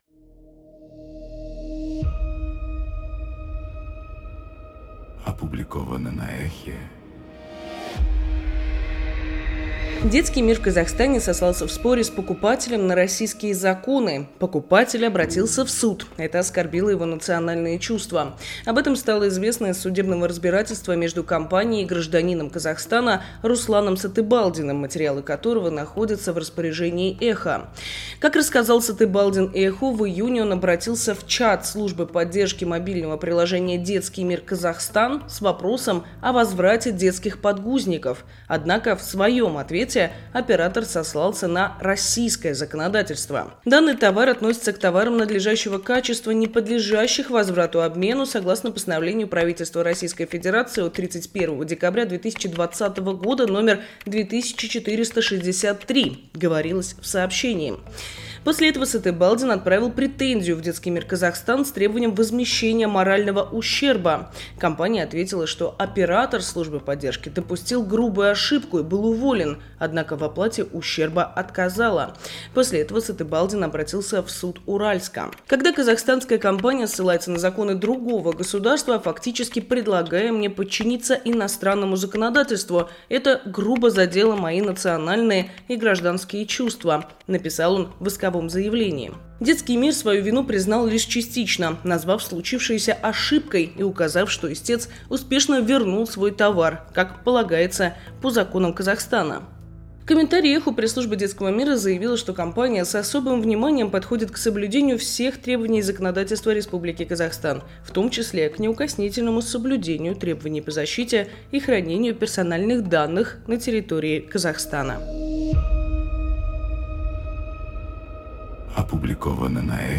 Читает